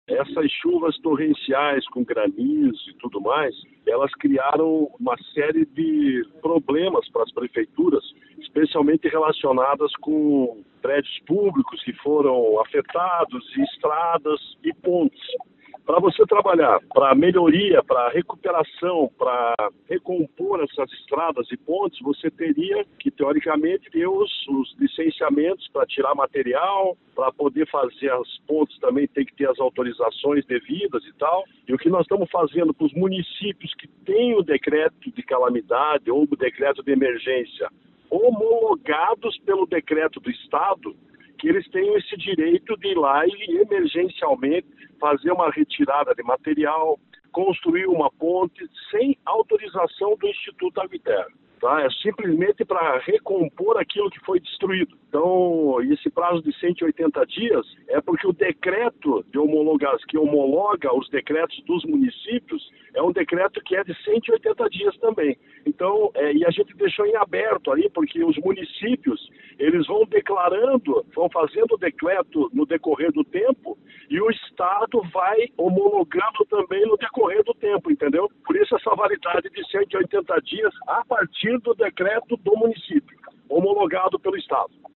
Sonora do diretor-presidente do IAT, Everton Souza, sobre a dispensa de licença ambiental para obras de reconstrução em cidades afetadas pela chuva